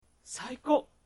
「喜」のタグ一覧
ボイス
男性